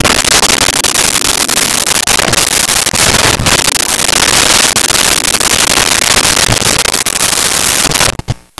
Cougar Hiss Growls
Cougar Hiss Growls.wav